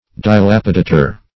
Search Result for " dilapidator" : The Collaborative International Dictionary of English v.0.48: Dilapidator \Di*lap"i*da`tor\, n. [Cf. F. dilapidateur.] One who causes dilapidation.